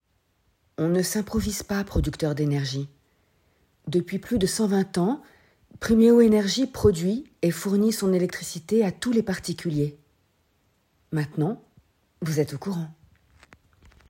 30 - 30 ans - Mezzo-soprano
doublage, voix off